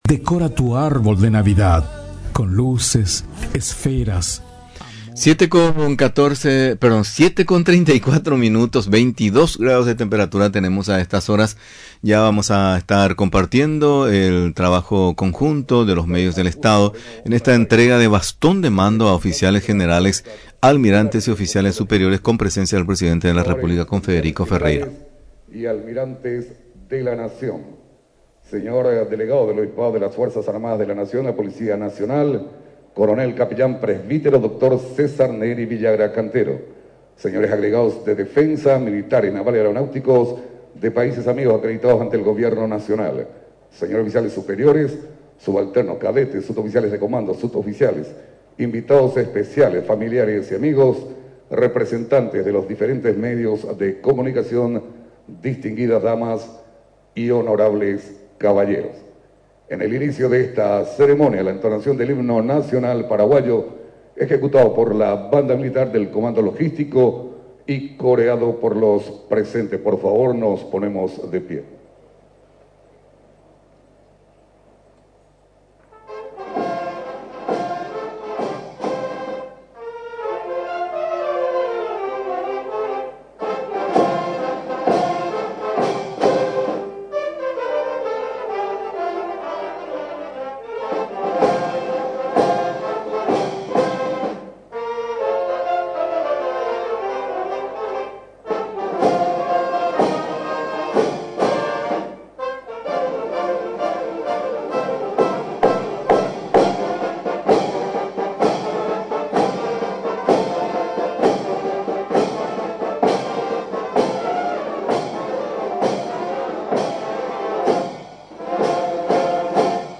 La entrega de Bastón de Mando a oficiales generales y almirantes, oficiales superiores, promovidos al grado inmediato superior se realizó durante una ceremonia presidida por el presidente de la República y comandante en jefe de las Fuerzas Armadas de la Nación, Santiago Peña, en el Comando del Ejército.